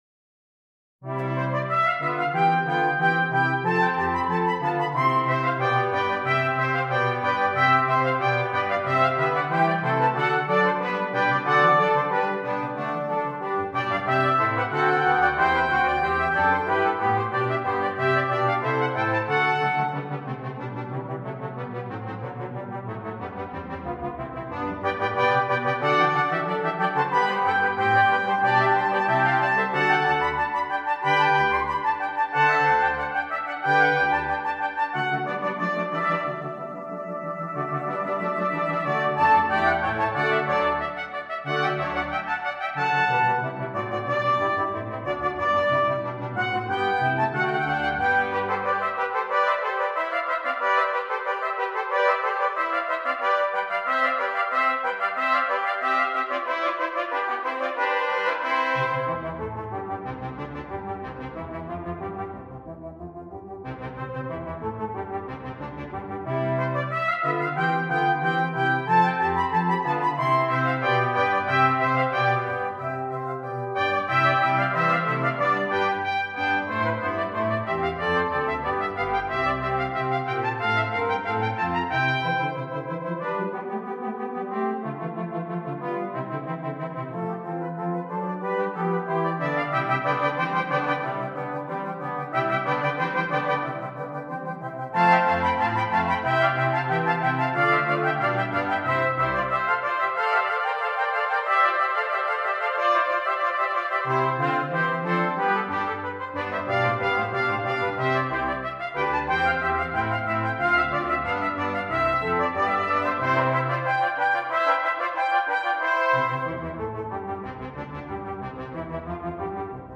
Brass Quintet and Solo Trumpet